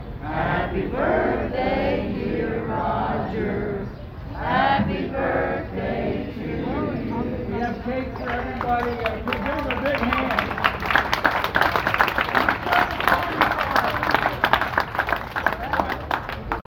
the crowd sing Happy Birthday.